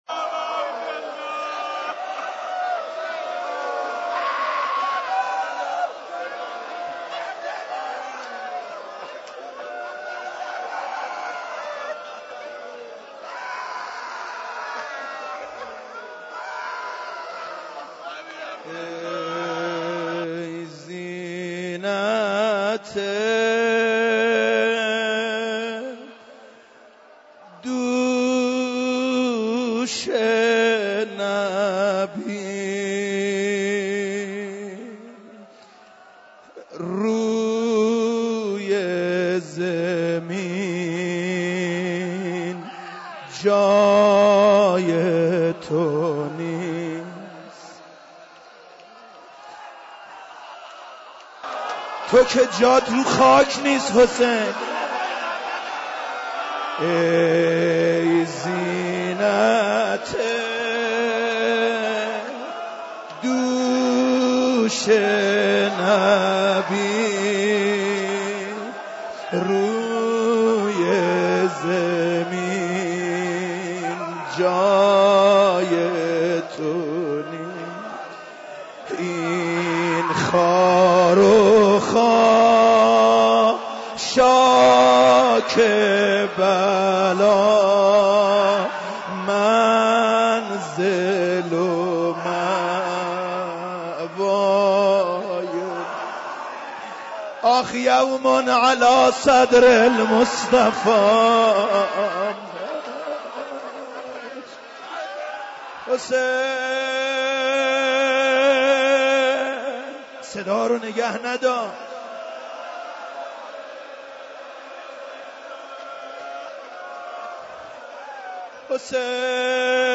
روز چهارم محرم97 - مسجد هدایت - روضه